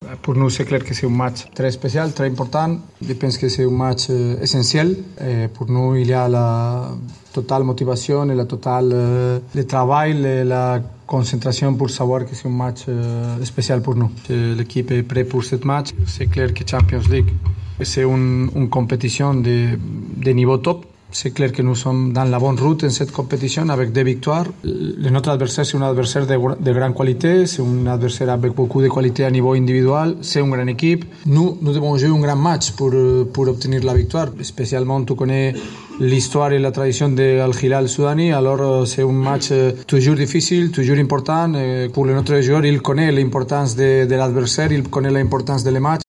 عقد مدرب النجم الساحلي خوان كارلوس قاريدو ندوة صحفية في قاعة الندوات في ملعب رادس الاولمبي صحبة اللاعب محمد امين بن عمر للحديث على اخر استعدادات النجم لمباراة الهلال السوداني يوم السبت في رادس انطلاقا من الساعة الخامسة مساء.